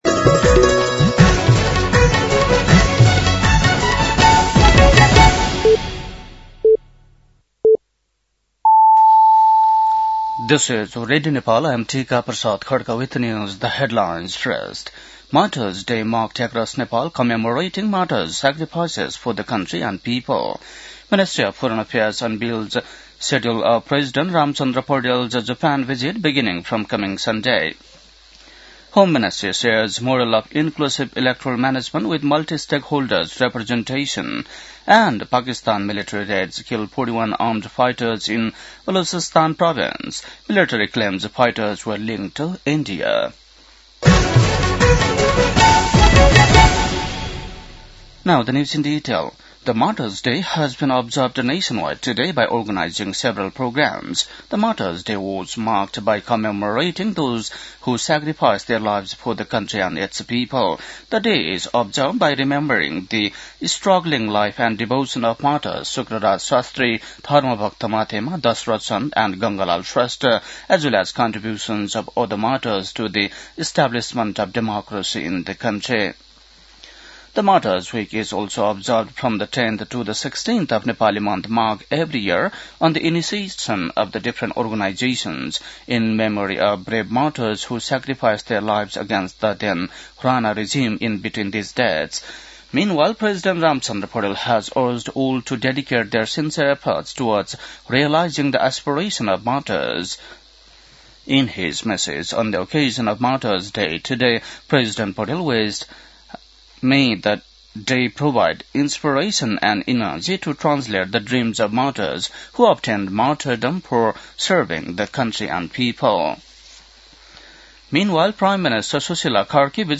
बेलुकी ८ बजेको अङ्ग्रेजी समाचार : १६ माघ , २०८२
8-pm-english-news-10-16.mp3